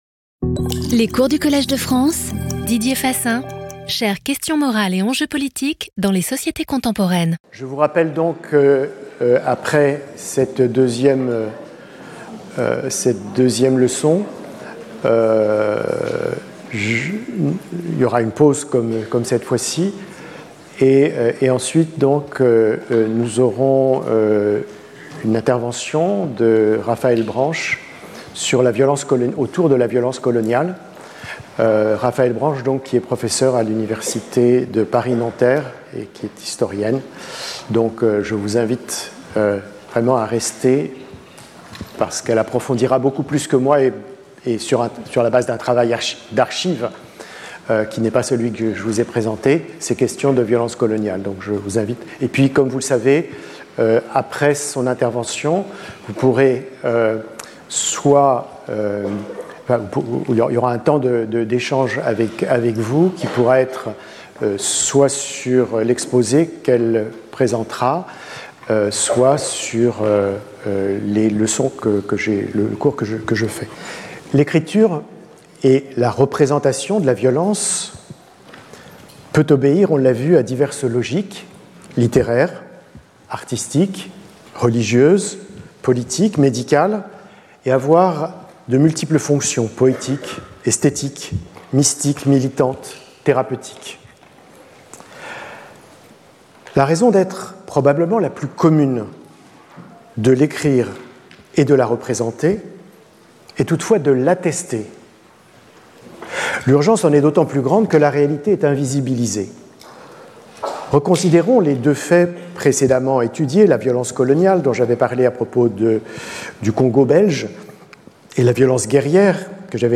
Didier Fassin Professeur du Collège de France
Cours